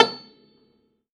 53x-pno16-C6.wav